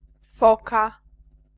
f f
foka feist